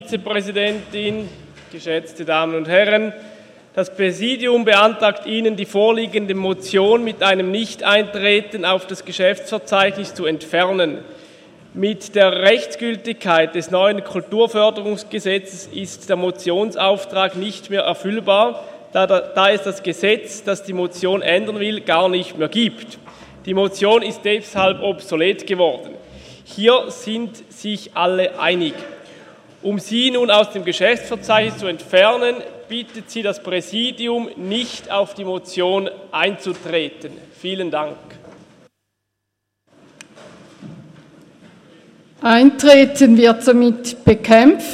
18.9.2017Wortmeldung
Session des Kantonsrates vom 18. bis 20. September 2017